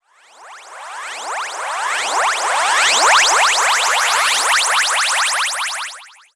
fxpTTE06005sweep.wav